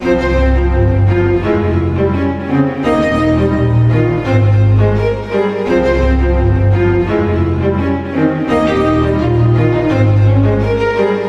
描述：室内弦乐合奏小提琴1，小提琴2，中提琴和大提琴。
Tag: 器乐 古典 现代 电影音乐